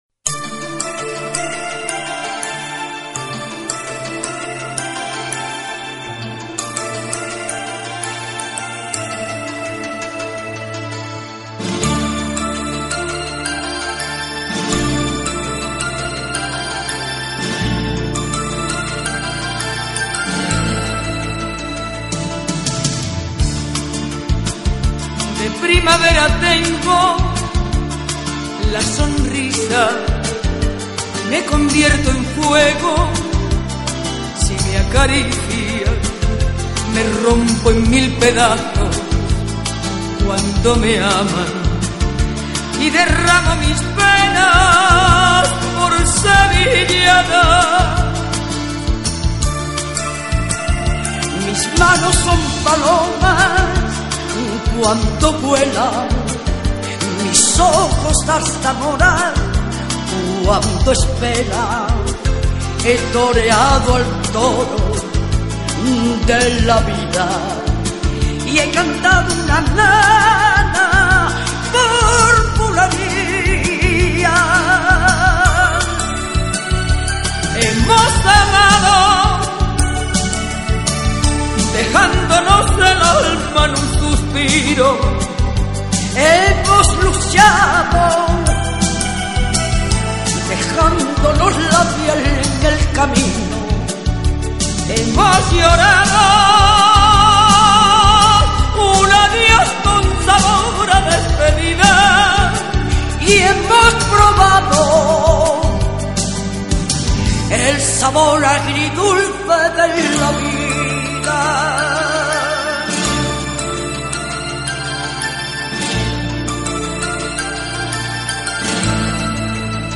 Carpeta: Lentos en español mp3